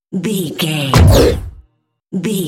Dramatic monster hit
Sound Effects
heavy
intense
dark
aggressive
hits
the trailer effect